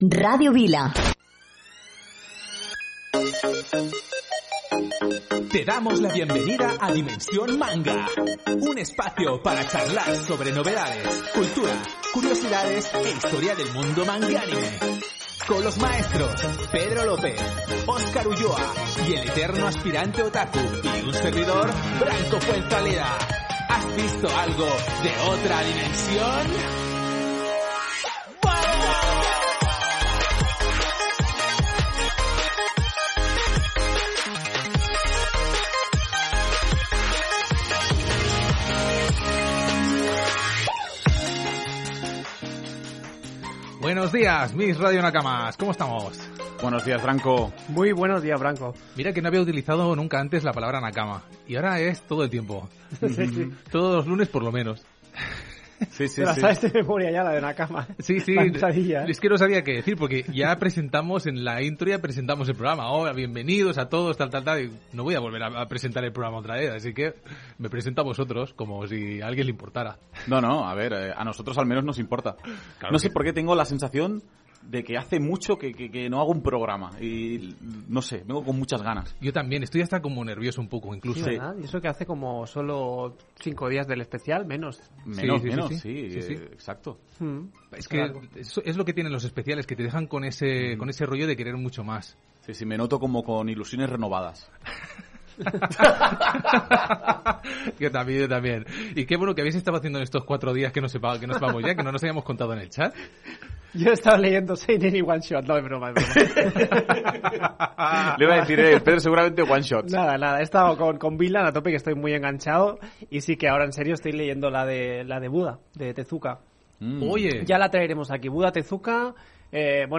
Indicatiu de l'emissora, careta del programa, presentació amb el diàleg sobre el que estan llegint i veient els integrants de l'equip